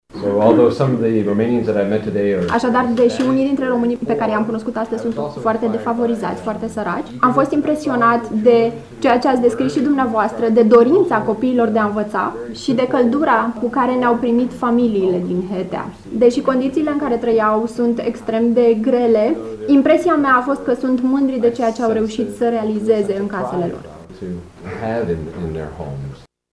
stiri-22-ian-Klemm.mp3